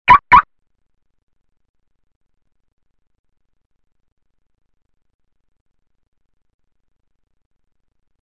Звуки автомобильной сигнализации
На этой странице собраны различные звуки автомобильных сигнализаций – от резких гудков до прерывистых тревожных сигналов.